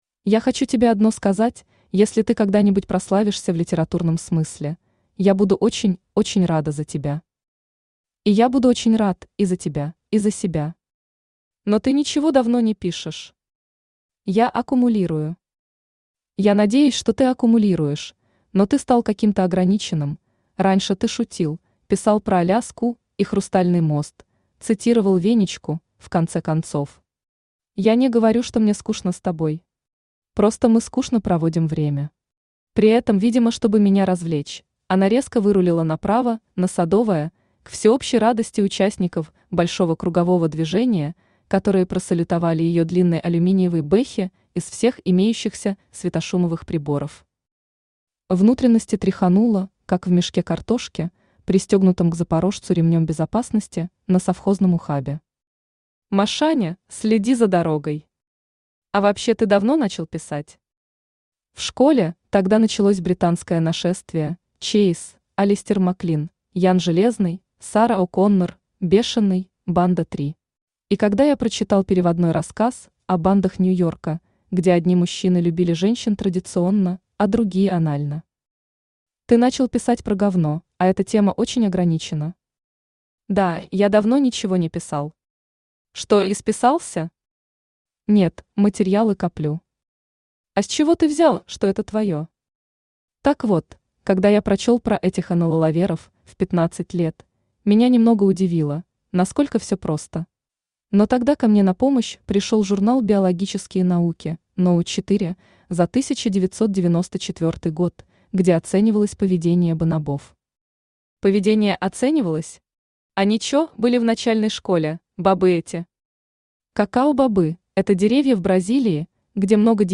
Аудиокнига Москва, я не люблю Тебя | Библиотека аудиокниг
Aудиокнига Москва, я не люблю Тебя Автор Тор Регнарек Читает аудиокнигу Авточтец ЛитРес.